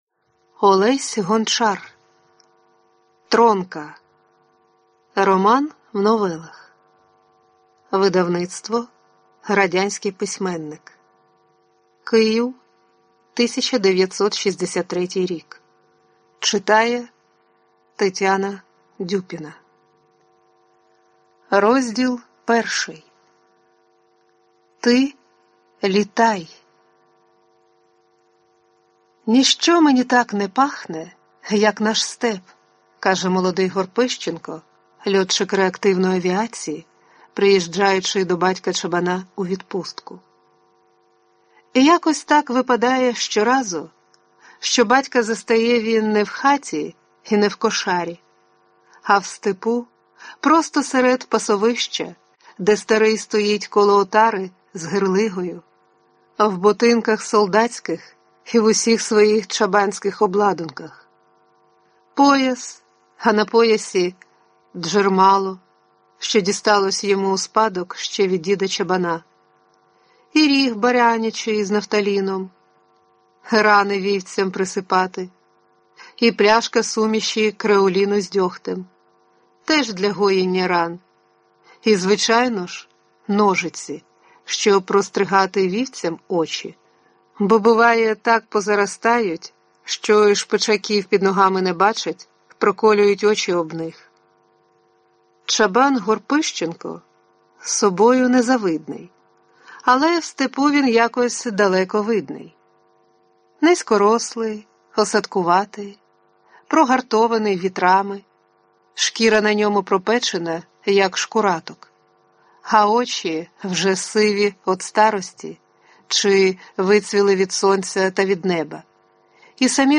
Аудиокнига Тронка. Роман | Библиотека аудиокниг